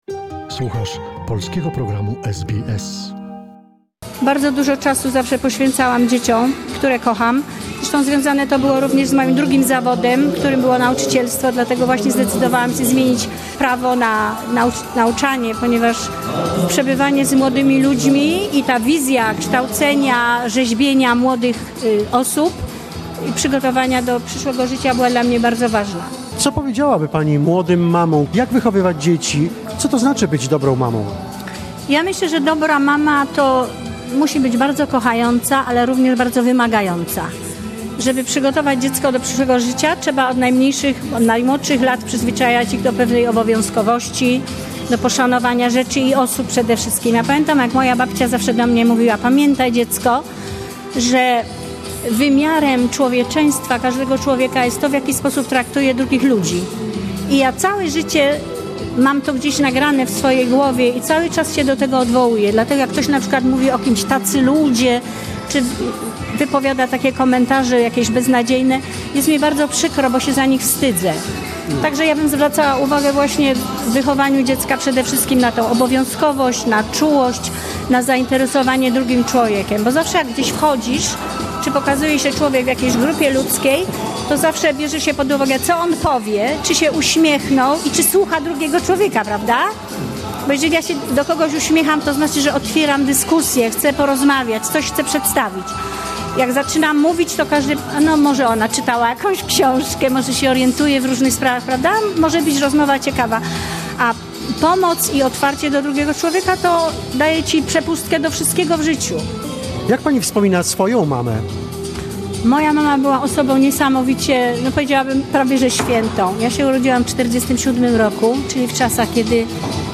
This is an archival recording ....